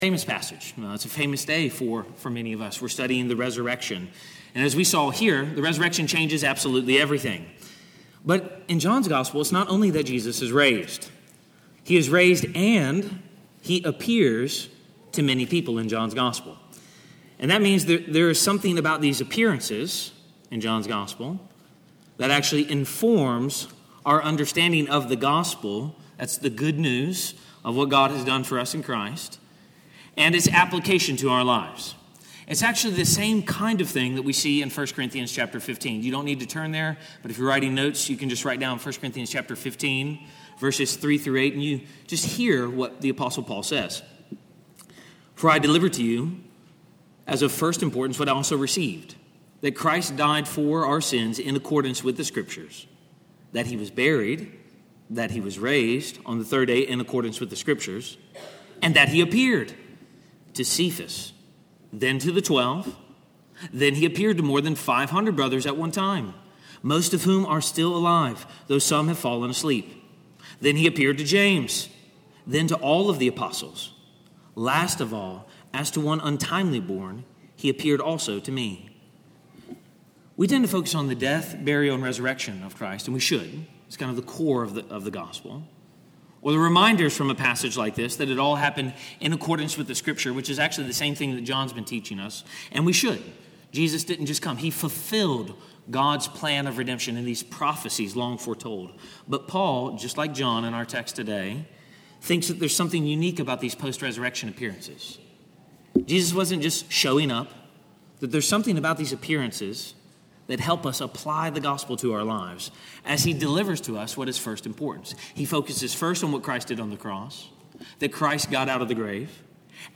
SERMON-54.mp3